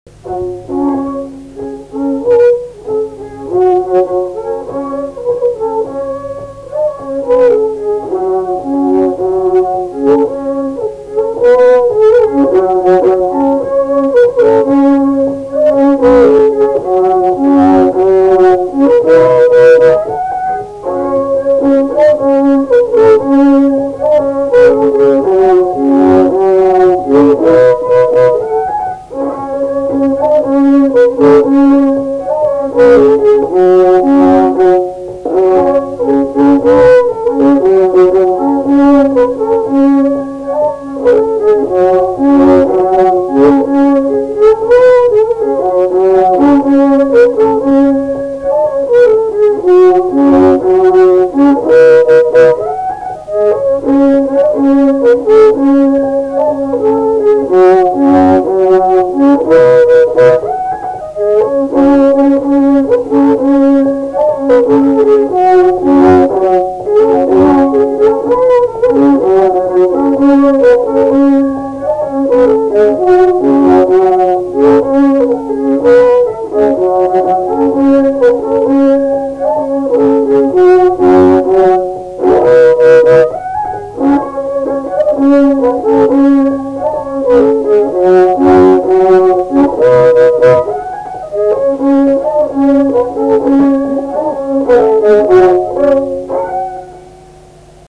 šokis